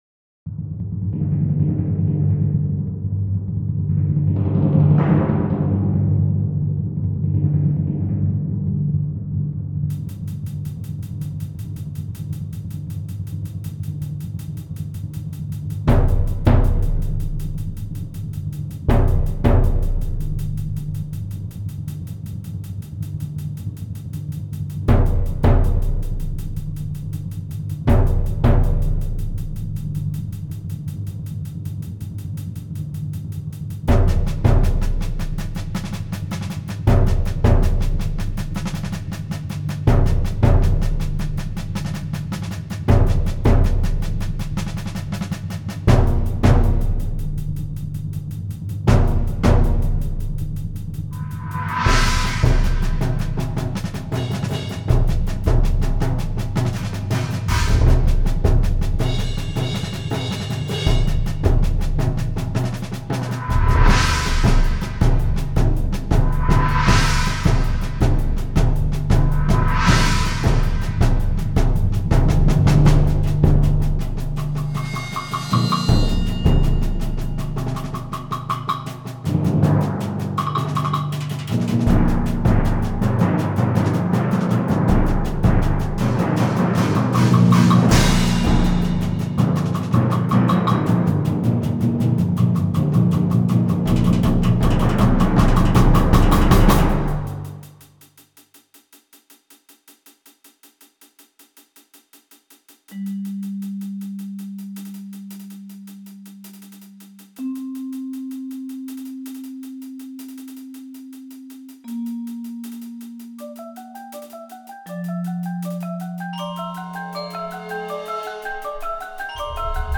Voicing: 7-11 Percussion